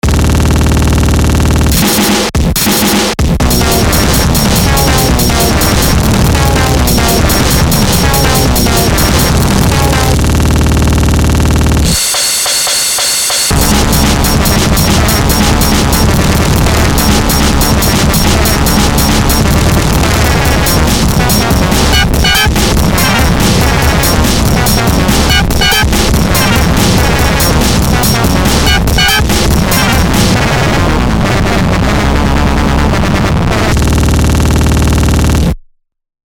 breakcore, shitcore, noisecore, glitch,